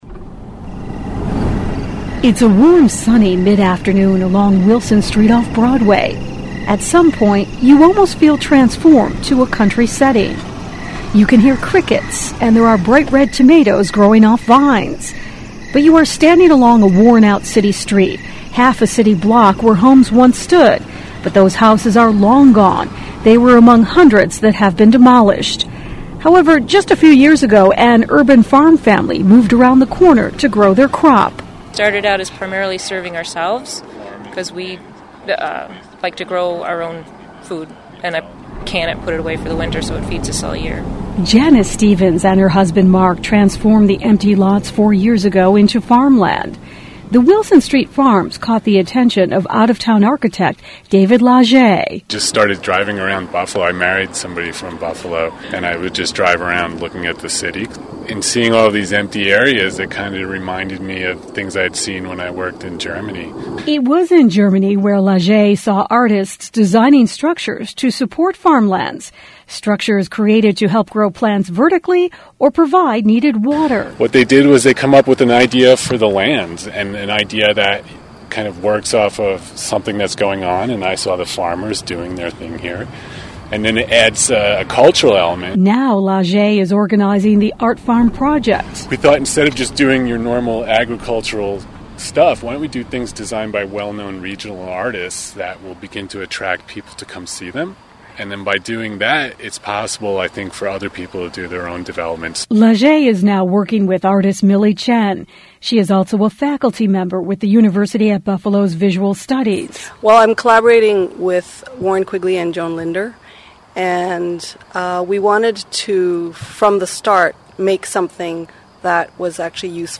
It’s a warm, sunny mid-afternoon on Wilson Street of Broadway.
You can hear crickets and there are bright, red tomatoes growing off vines, but you are standing along a worn out city street -half of a city block where homes on once side.